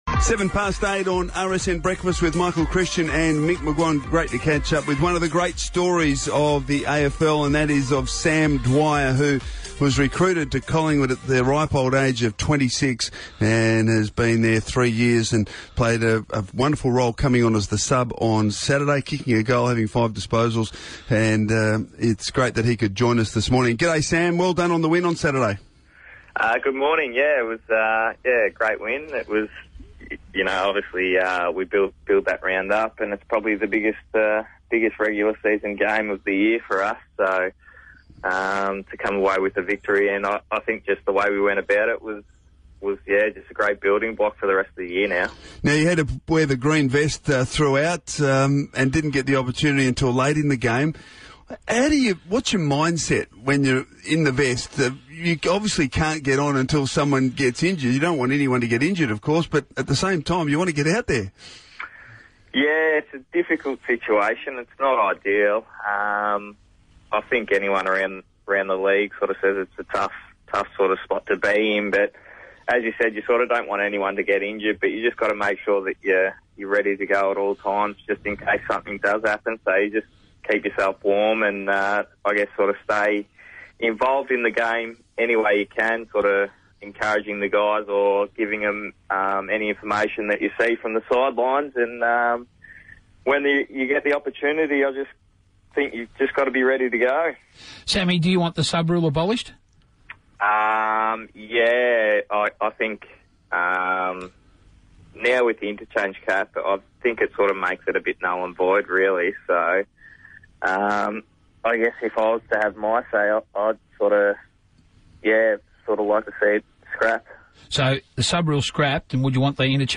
Radio: Sam Dwyer on RSN
Listen to Sam Dwyer join premiership Magpies Michael Christian and Mick McGuane on Radio Sports National on Thursday 30 April 2015.